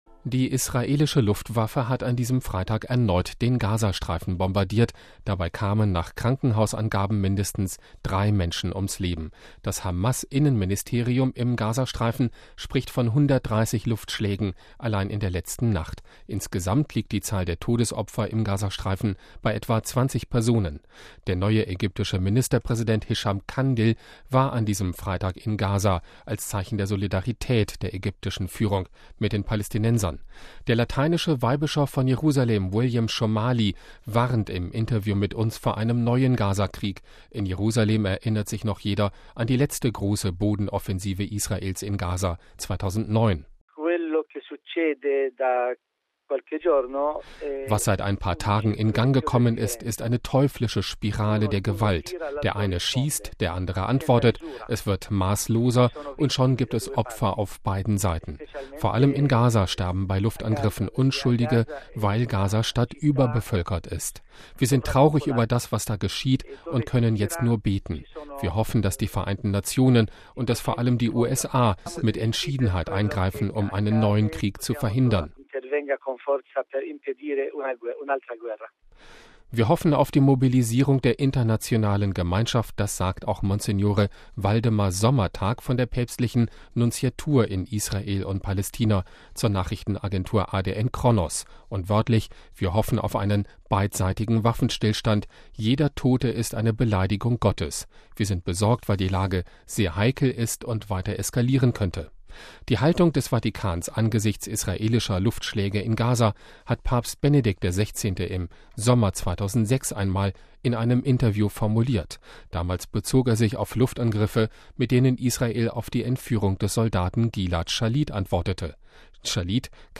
Der Lateinische Weihbischof von Jerusalem, William Shomali, warnt im Interview mit Radio Vatikan vor einem neuen Gaza-Krieg.
Die Haltung des Vatikans angesichts israelischer Luftschläge in Gaza hat Papst Benedikt XVI. im Sommer 2006 einmal in einem Interview mit Radio Vatikan formuliert.